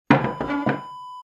Wooden chair set down sound effect .wav #3
Description: The sound of setting down a wooden chair on the floor
Properties: 48.000 kHz 16-bit Stereo
A beep sound is embedded in the audio preview file but it is not present in the high resolution downloadable wav file.
Keywords: wooden, chair, set, set, place, placing, put, putting, down, floor
wooden-chair-set-down-preview-3.mp3